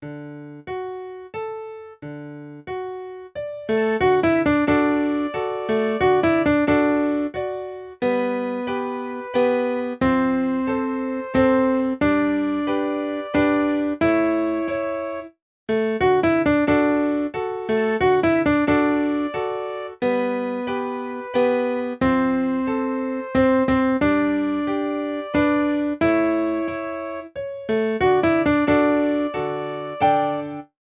These songs teach you (or your students) basic triplet rhythms with a fun Valentine’s Day theme.
The Valentine Rhythm Song is a fun rhythm song for any holiday school recital and is a good practice piece for primary choirs or beginning piano students.